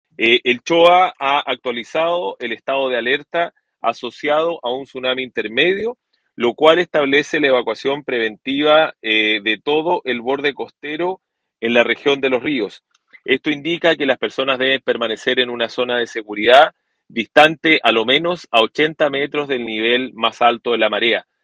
Al respecto, el Delegado Presidencial Regional, César Asenjo, confirmó que se actualizó el Estado de Precaución en la Región de Los Ríos y se estableció Alerta de Tsunami, llamando a evacuar el borde costero.